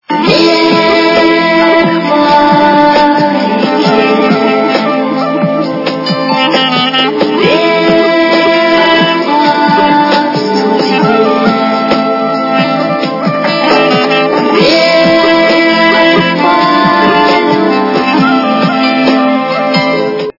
украинская эстрада
При заказе вы получаете реалтон без искажений.